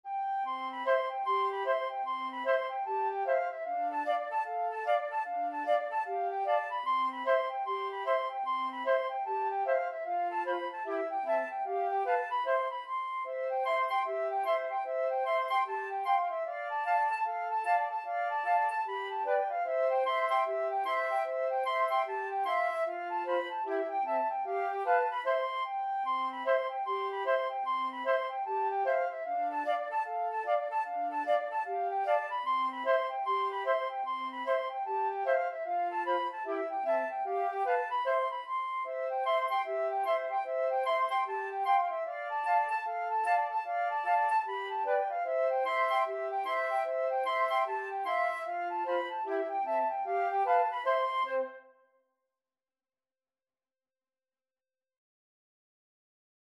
C major (Sounding Pitch) (View more C major Music for Flute Trio )
4/4 (View more 4/4 Music)
Flute Trio  (View more Intermediate Flute Trio Music)
Traditional (View more Traditional Flute Trio Music)